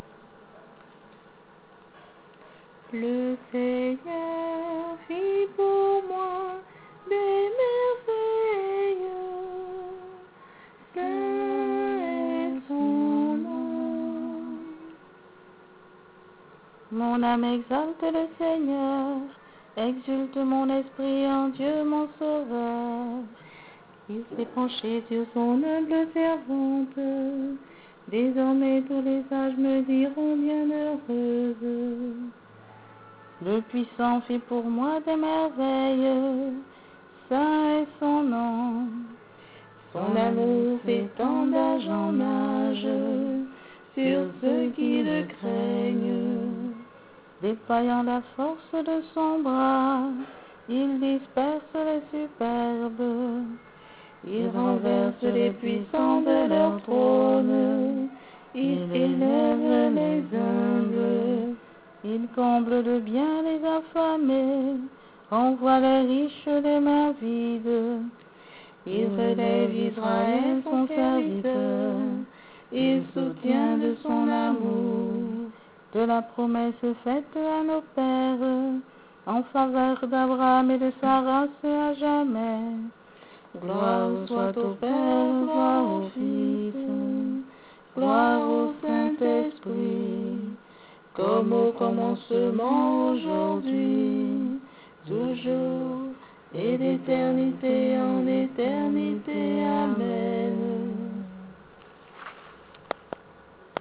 Enregistrement réalisé avec les bruits d'ambiance!